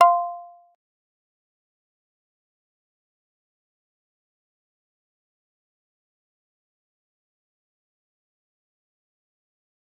G_Kalimba-F5-pp.wav